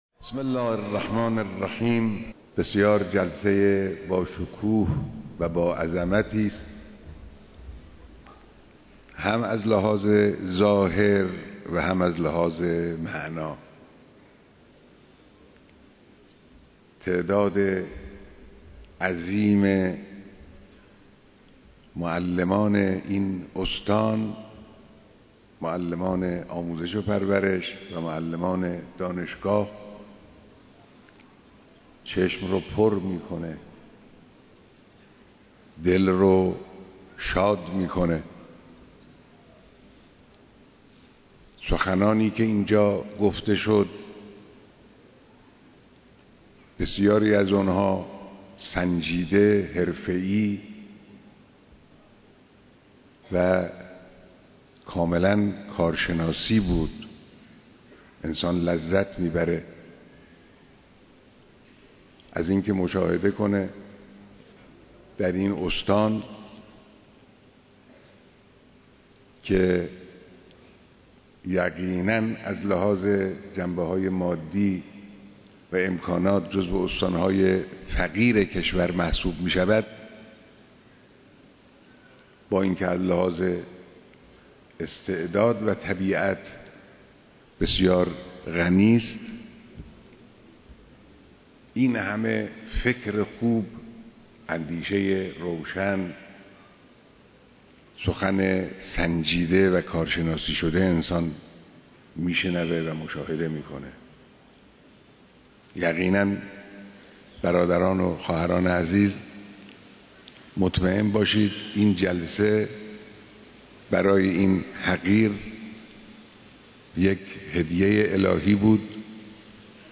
بيانات در ديدار معلمان و اساتيد استان خراسان شمالى‌